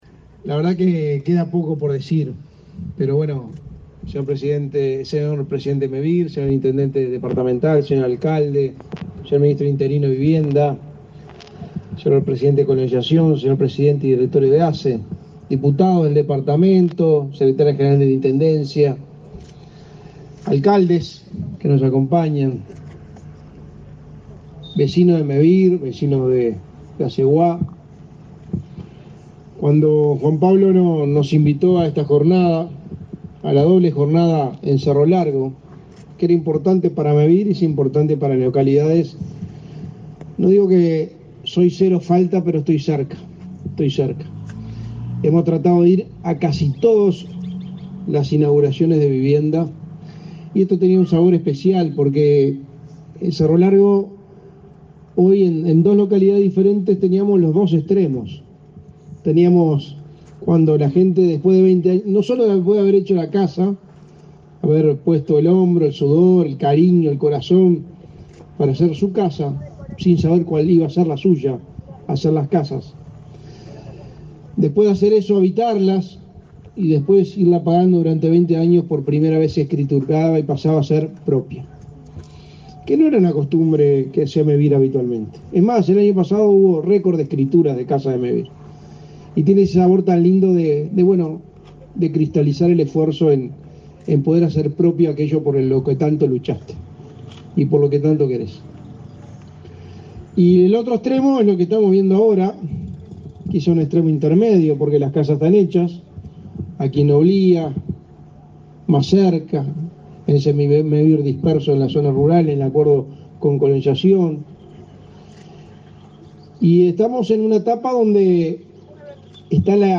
Conferencia de prensa por la inauguración de obras en Cerro Largo
Conferencia de prensa por la inauguración de obras en Cerro Largo 27/04/2023 Compartir Facebook X Copiar enlace WhatsApp LinkedIn Mevir inauguró, este 27 de abril, 26 soluciones habitacionales en Noblía y Aceguá, en Cerro Largo. En el evento participó el secretario de la Presidencia, Álvaro Delgado, y el presidente del Instituto Nacional de Colonización, Julio Cardozo.